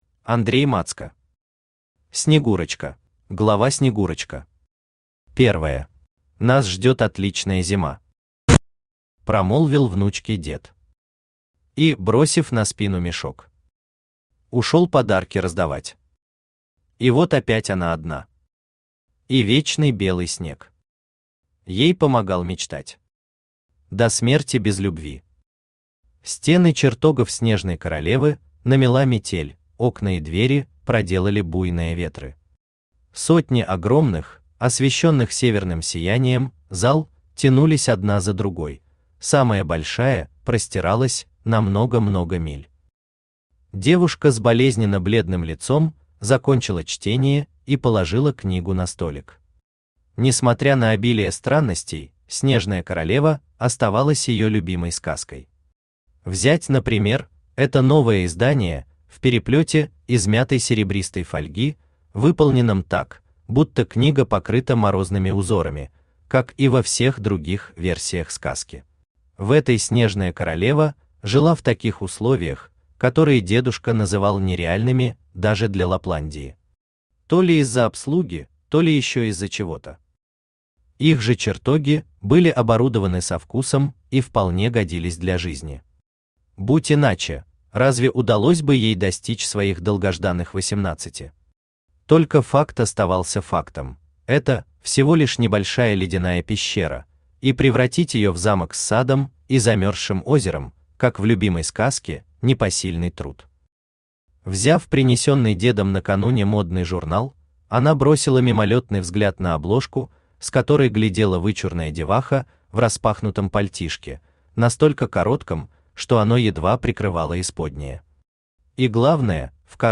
Аудиокнига Снегурочка | Библиотека аудиокниг
Aудиокнига Снегурочка Автор Андрей Мацко Читает аудиокнигу Авточтец ЛитРес.